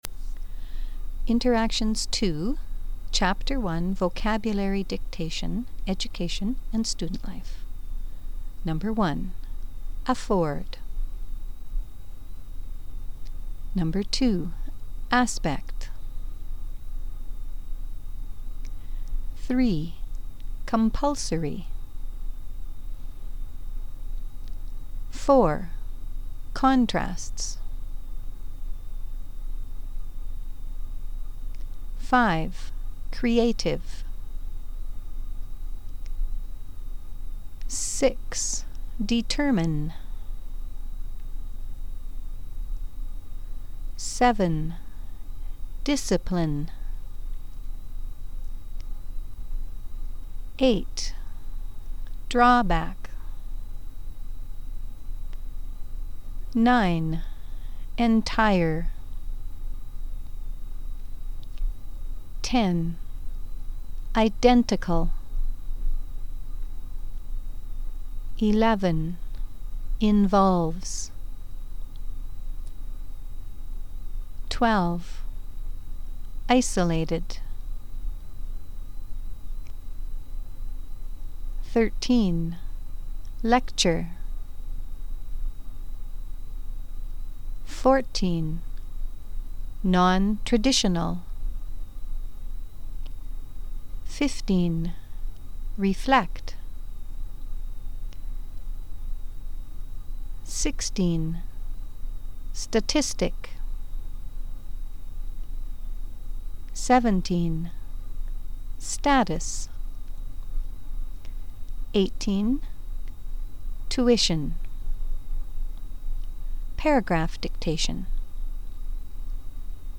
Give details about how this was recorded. First you will hear a list of words. Then you will hear a paragraph dictation.